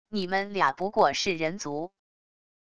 你们俩不过是人族wav音频生成系统WAV Audio Player